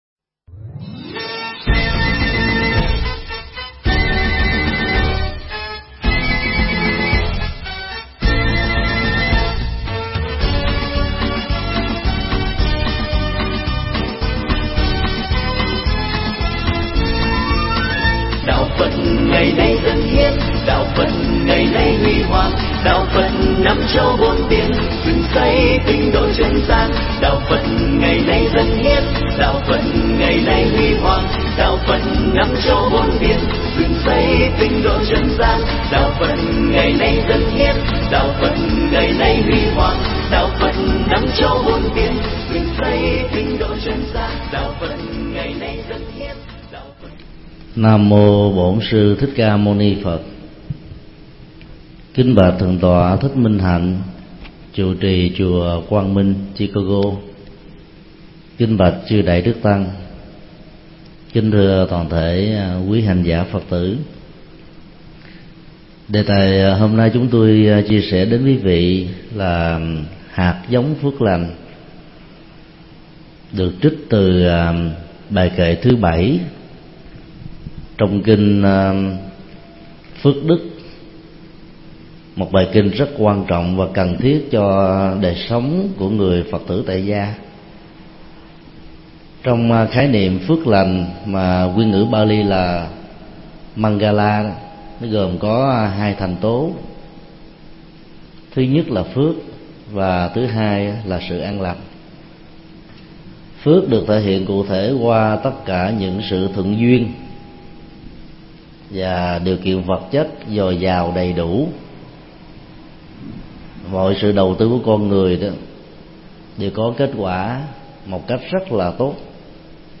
Tải mp3 Pháp thoại Kinh Phước Đức 4: Hạt giống phước lành
giảng tại Chùa Quang Minh, Chicago